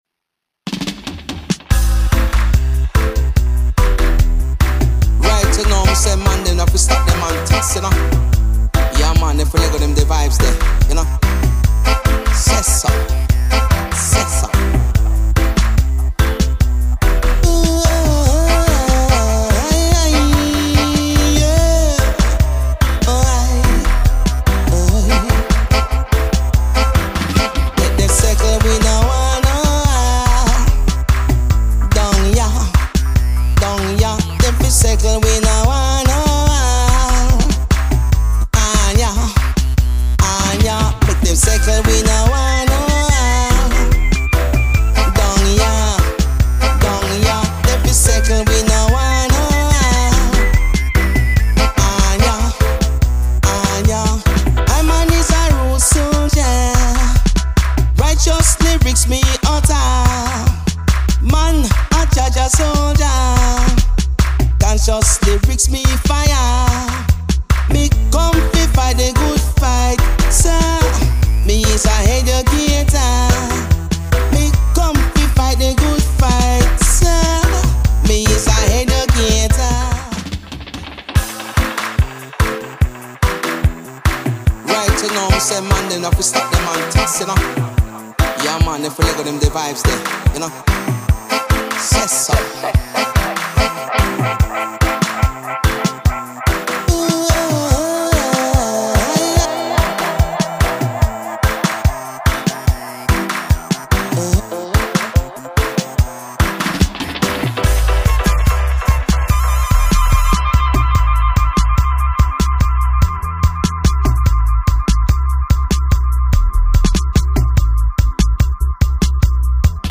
Conscious message